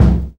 JAZZ KICK 4.wav